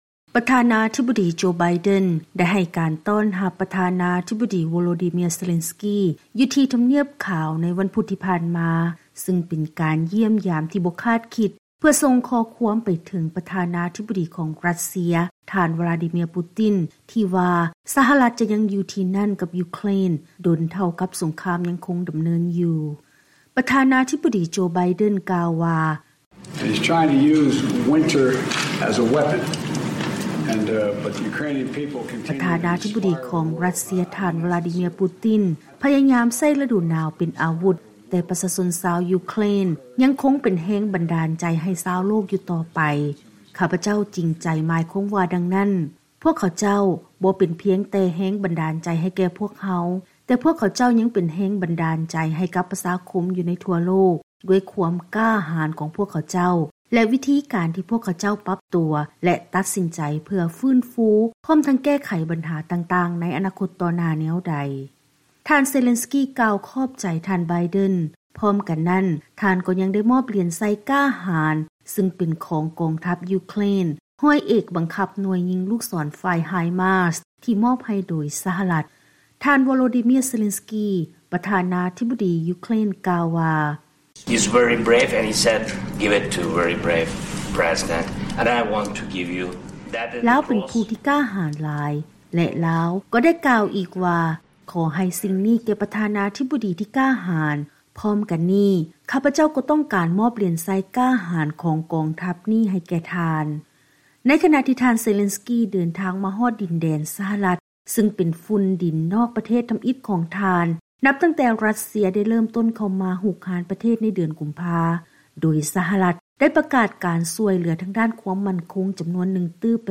ເຊີນຮັບຟັງບົດລາຍງານກ່ຽວກັບ ການເດີນທາງມາສະຫະລັດຂອງ ທ່ານເຊເລັນສກີ